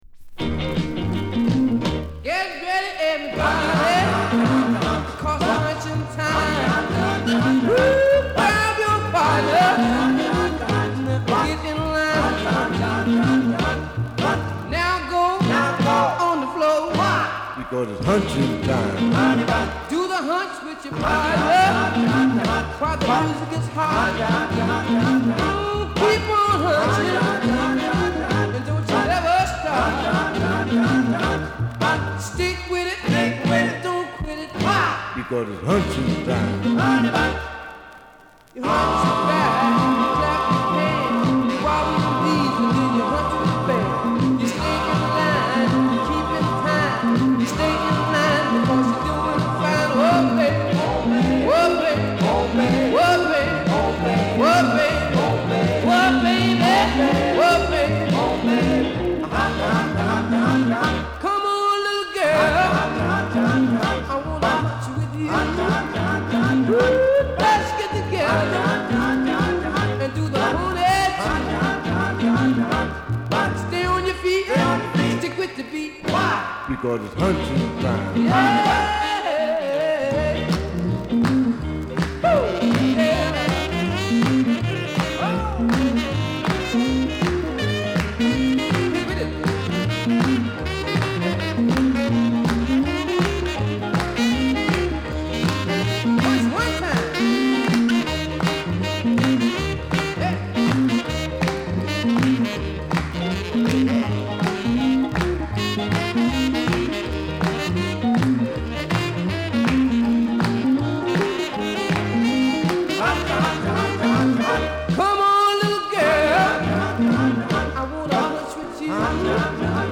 オリジナルよりピッチが早く、なおかつラフな仕上がりでこの人ならでは味わいがしっかりと音に反映されている。